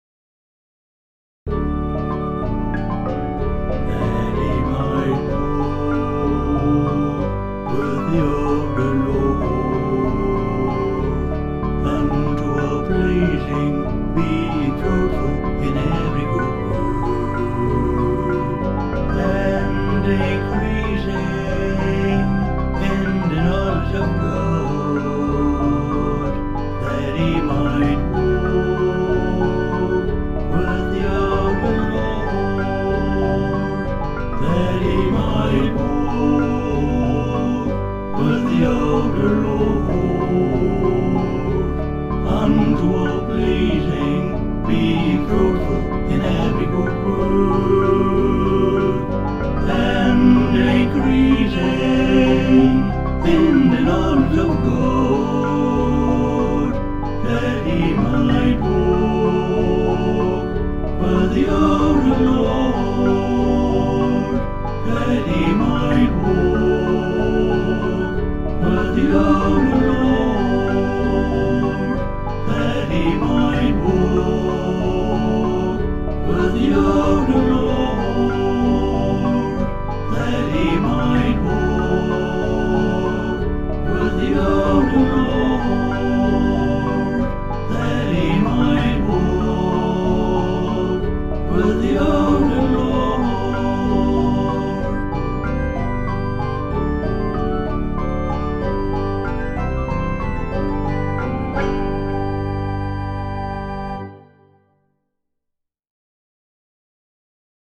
With joyful expectancy